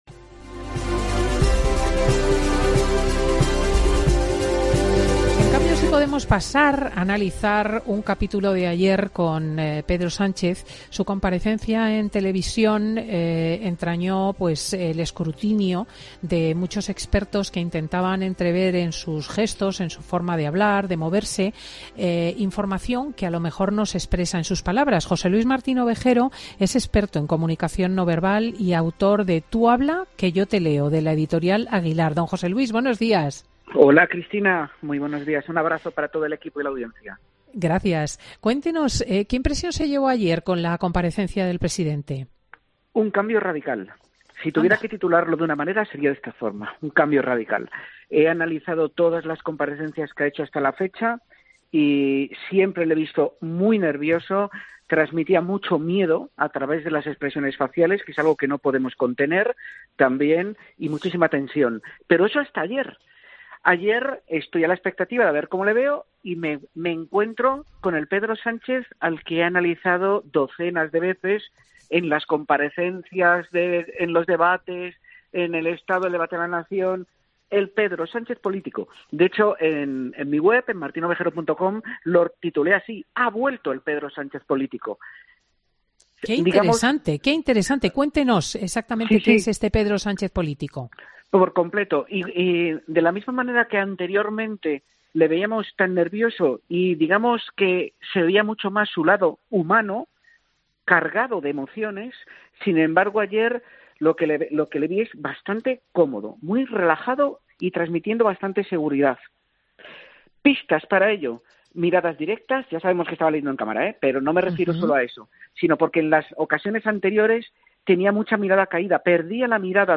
Escucha todos los detalles en la entrevista completa con Cristina López Schlichting.